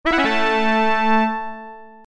Download Fanfare sound effect for free.
Fanfare